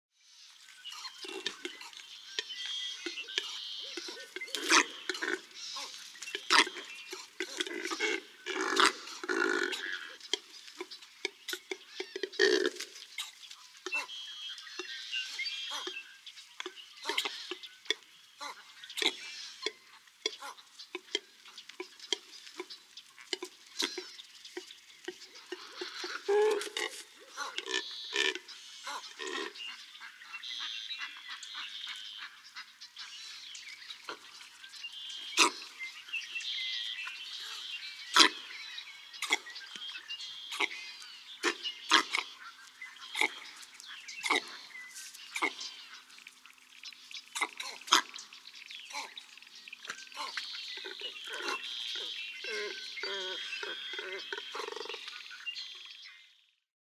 Double-crested cormorant
Waking calls in the roost, sounding more than a little like grunting pigs.
Anhinga Trail, Everglades National Park.
035_Double-crested_Cormorant.mp3